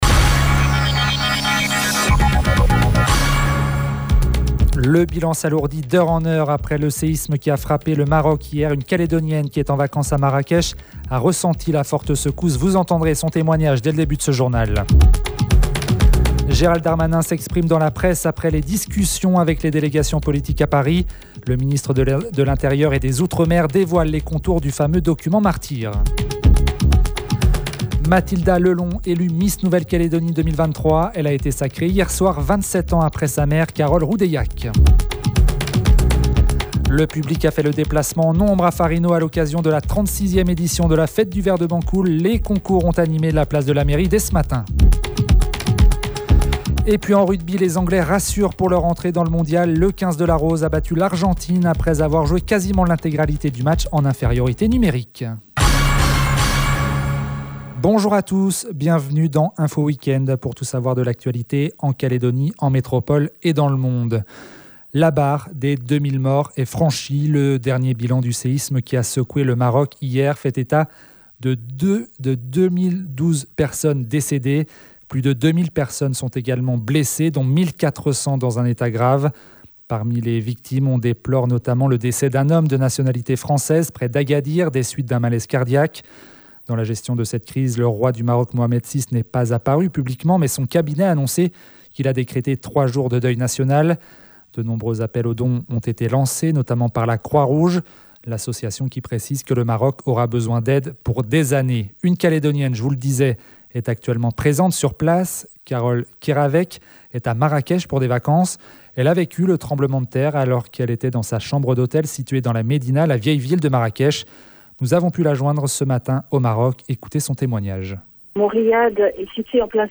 Vous avez pu entendre son témoignage depuis le Maroc dans le journal de midi.